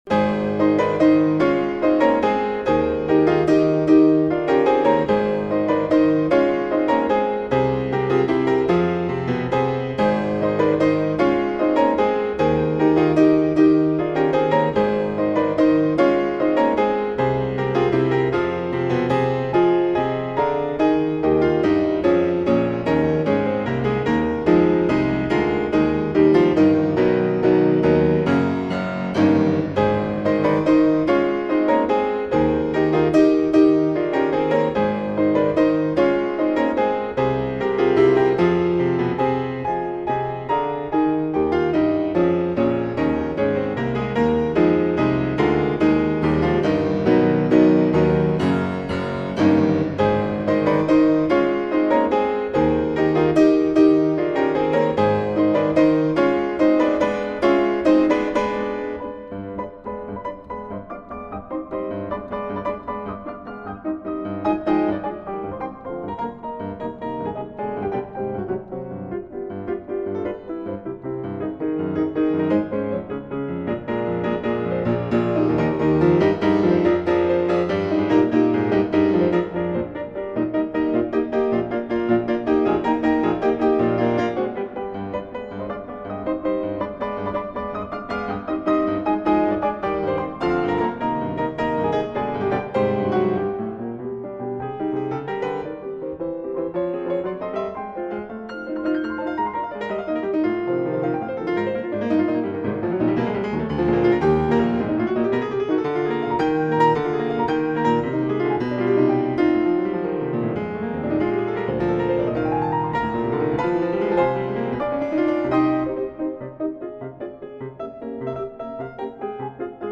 Pjesė užbaigiama vis greitėjančiu tempu, įtikinama davidsbiundlerių pergale.